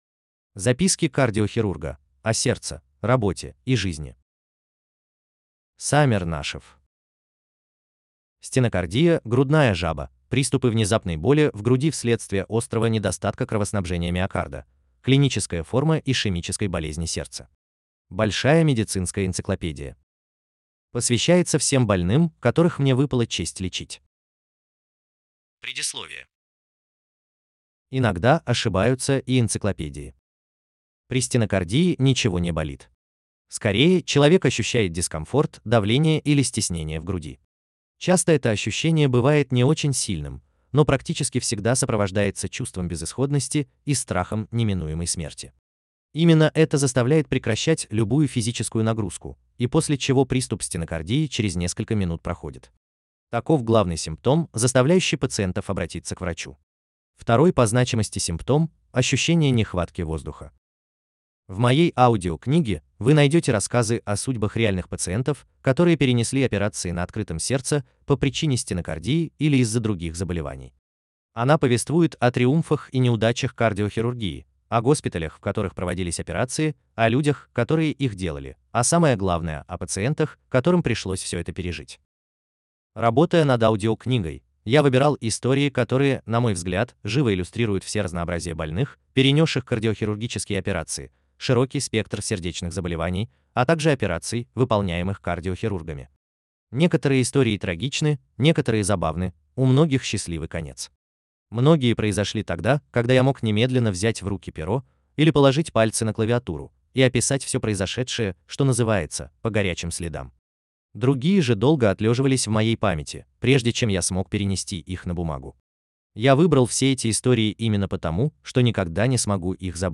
Аудиокнига Записки кардиохирурга | Библиотека аудиокниг